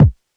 Kicks
DJP_KICK_ (158).wav